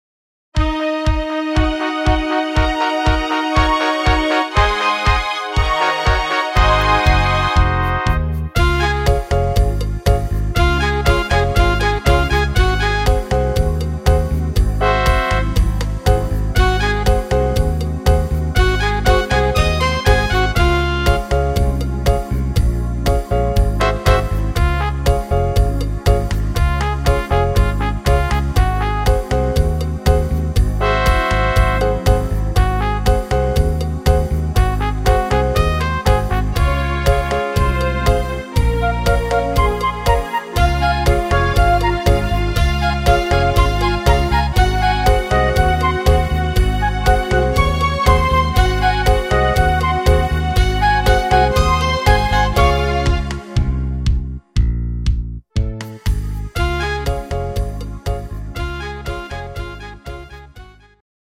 instr. Trompete